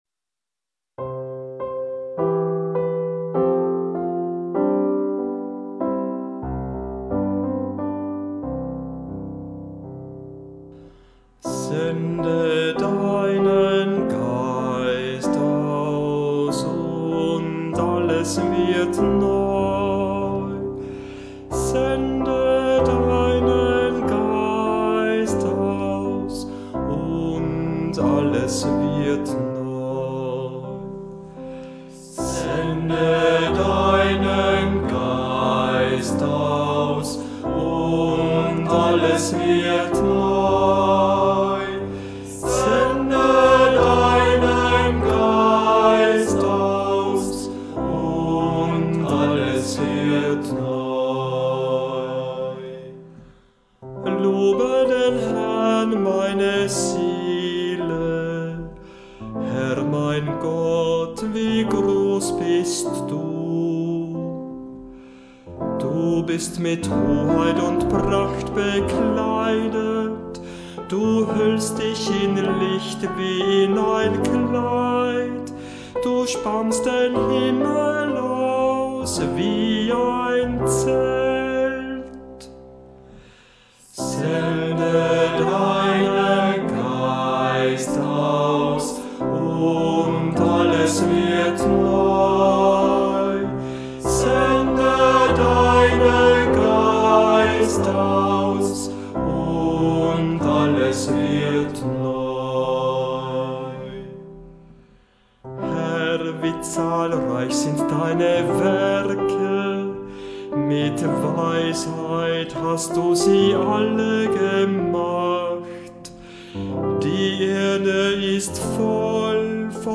Sie dienen als ersten Höreindruck der Psalmen, bei denen der Kehrvers als fester Bestandteil integriert ist und deshalb umso schöner erklingen, je mehr sich beim Singen des Kehrverses beteiligen.
psalm_104_pfingstsonntag-11.mp3